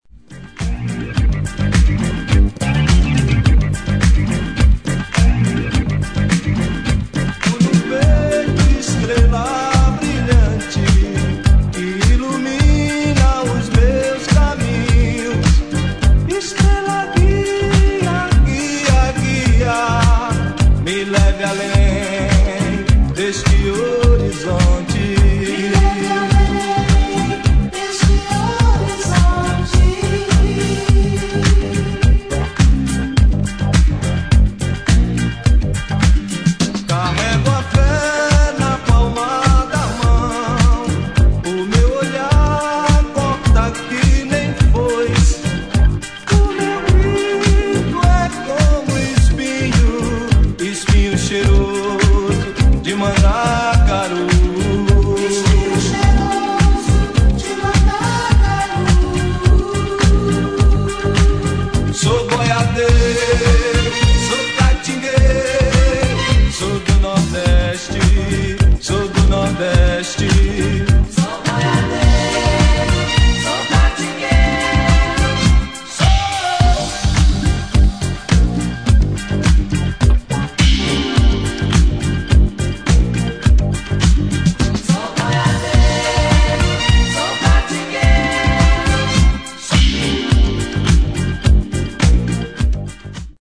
[ DISCO / JAZZ ]